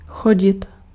Ääntäminen
IPA : /wɔːk/